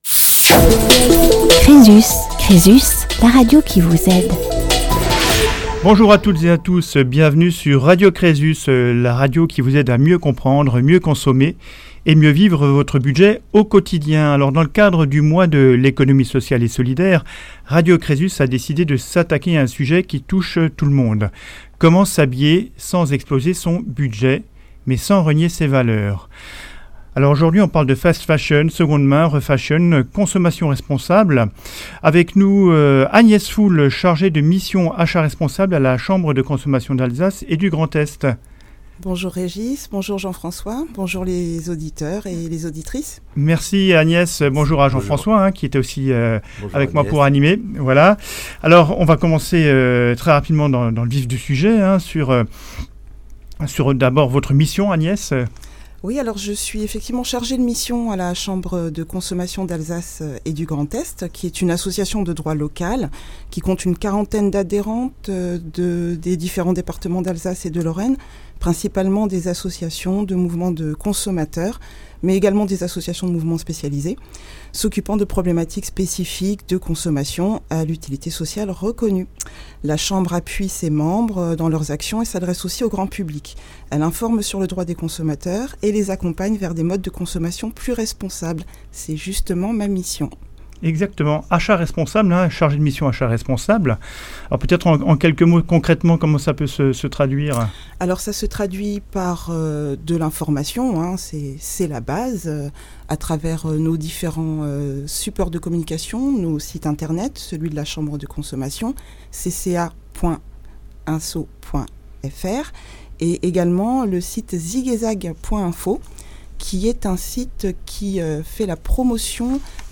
Emission spéciale mois de l’ESS – novembre 2025